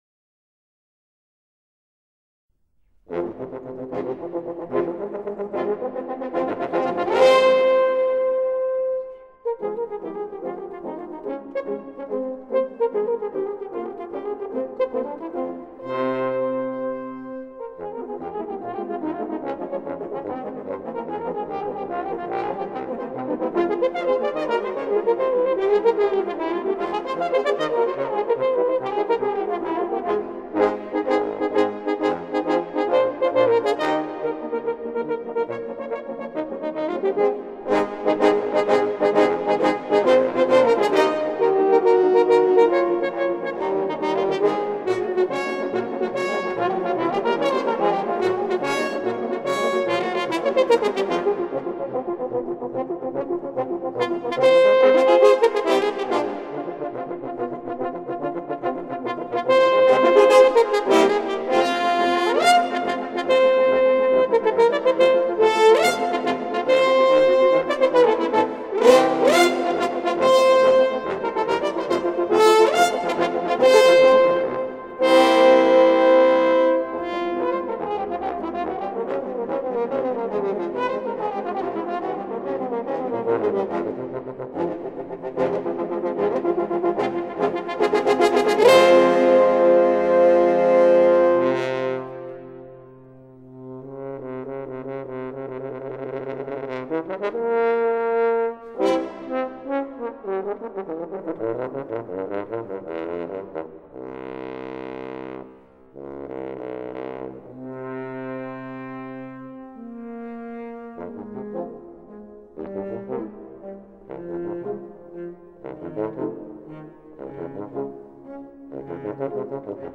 以铜管乐器中最悠扬深邃的声音，